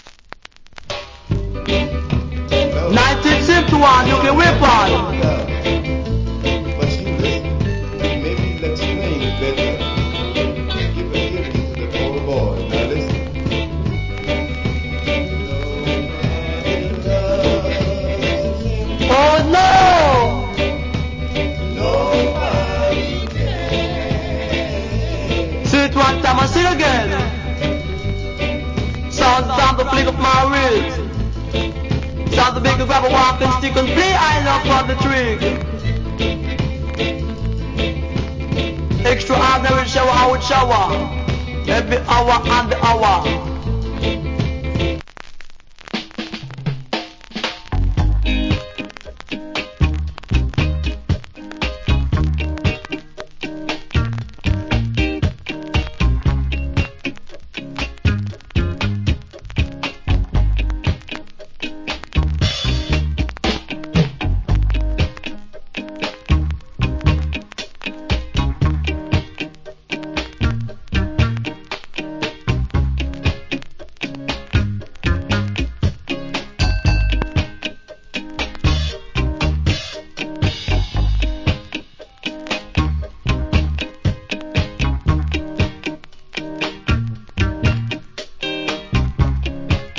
Cool DJ.